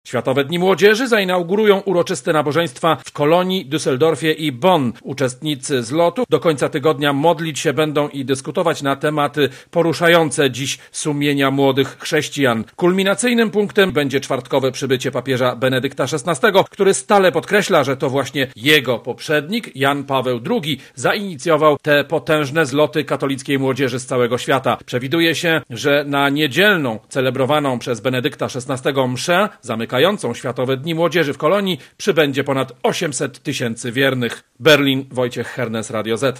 Relacja
swiatowe_dni_mlodzierzy_-_kolonia.mp3